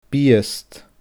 biast /biəsd/